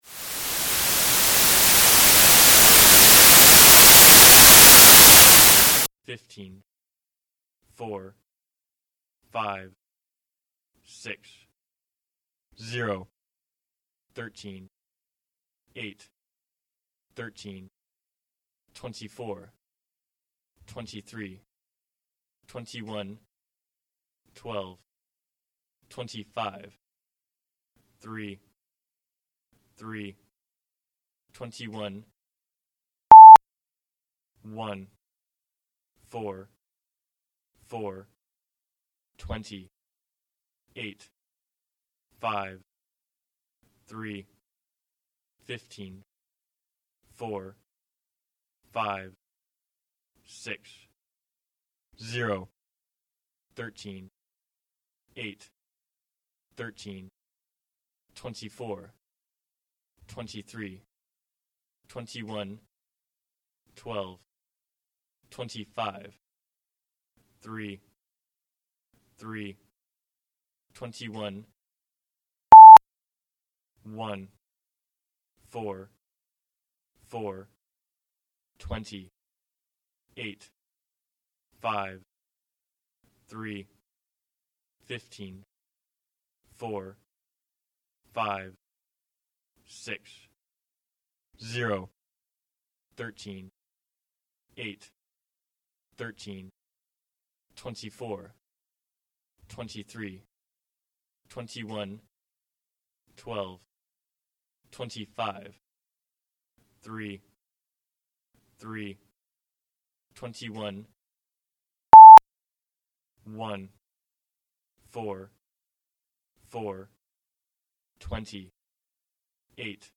This radio transmission was a sequence of numbers, followed by a beep. By decoding the numbers into letters, teams got ADDTHECODE, followed by gibberish.
groan_hour_broadcast.mp3